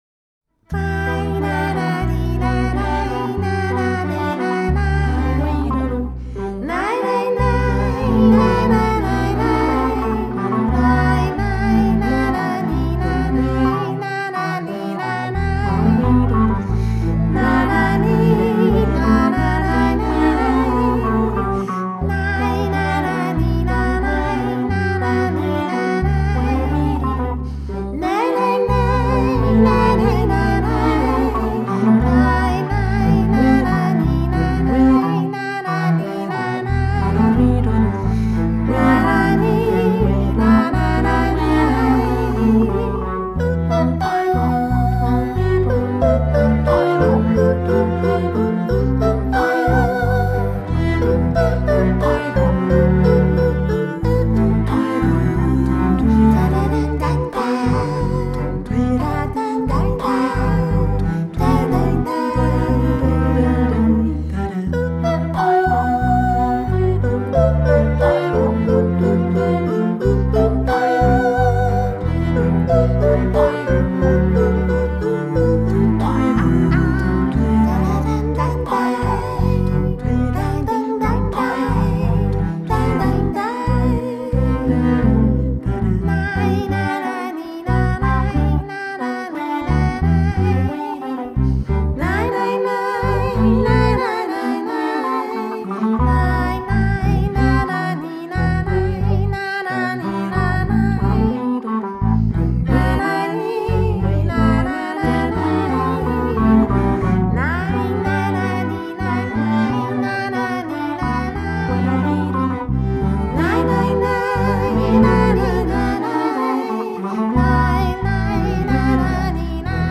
3 voix
3 violoncelles
2 basses